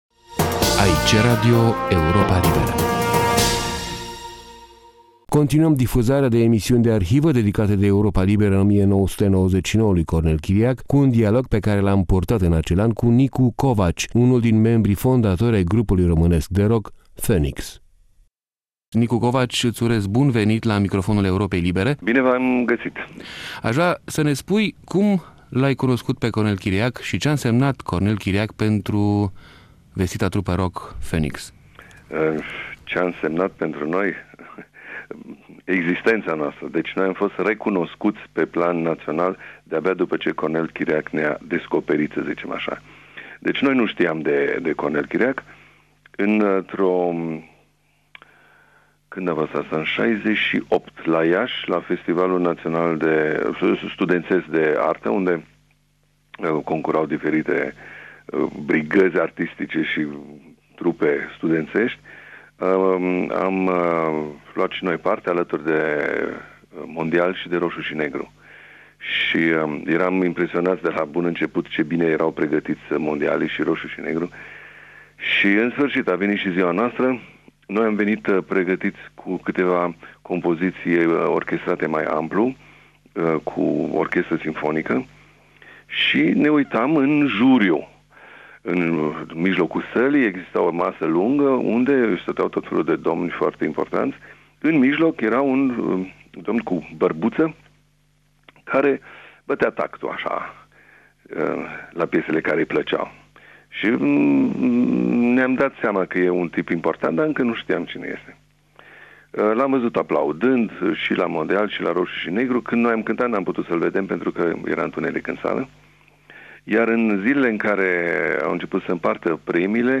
Interviul cu Nicu Covaci din seria comemorativă „Cornel Chiriac” 1999-2000 e urmat de emisiunea Metronom din 18 ianuarie 1975 dedicată trupei Baker Gurvitz Army,.